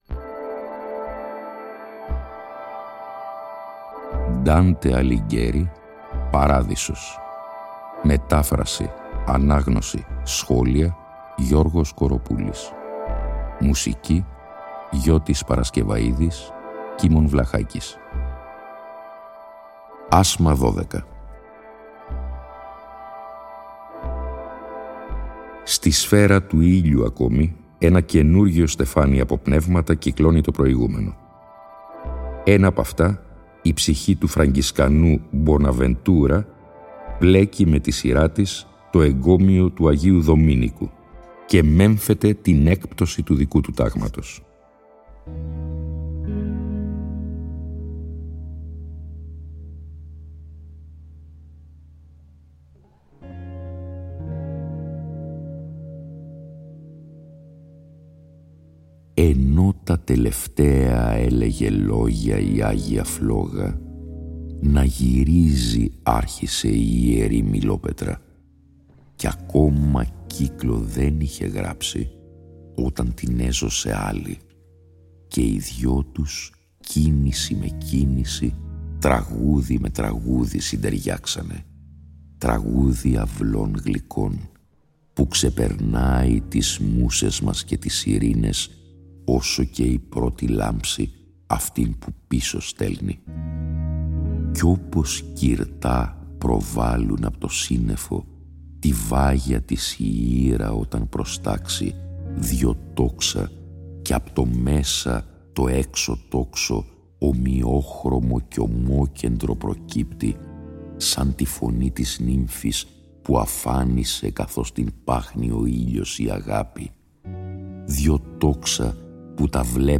Η ανάγνωση των 33 ασμάτων του «Παραδείσου», σε 20 ημίωρα επεισόδια, (συνέχεια της ανάγνωσης του «Καθαρτηρίου», που είχε προηγηθεί) συνυφαίνεται και πάλι με μουσική την οποία συνέθεσαν ο Γιώτης Παρασκευαΐδης και ο Κίμων Βλαχάκης, που ανέλαβε και την επιμέλεια ήχου. Η μετάφραση τηρεί τον ενδεκασύλλαβο στίχο και υποτυπωδώς την terza rima του πρωτοτύπου – στο «περιεχόμενο» του οποίου παραμένει απολύτως πιστή.